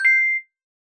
Coins (5).wav